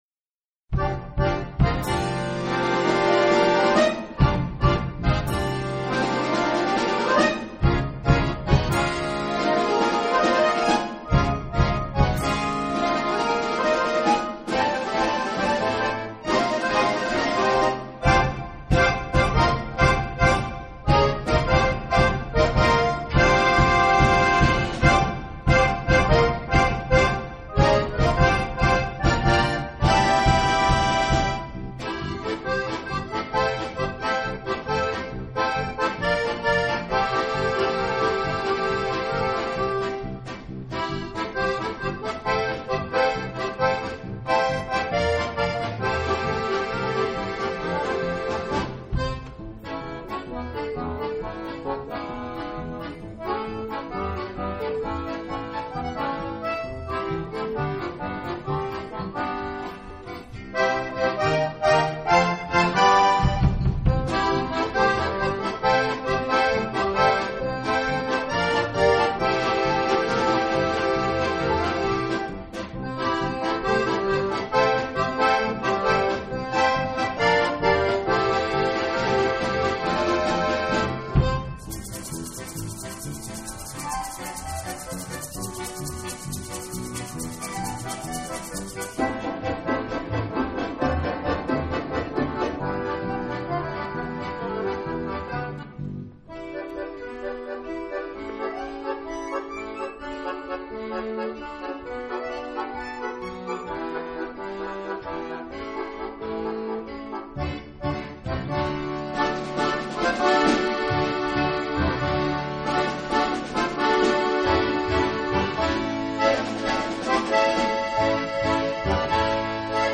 Intercity (Rhythmische Impression von Adolf Götz)